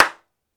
Hand Clap Sound
cartoon
Hand Clap